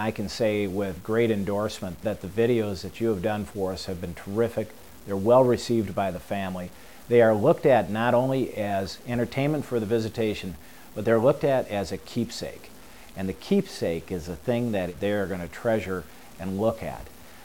To listen to what a funeral director has to say about our funeral video services.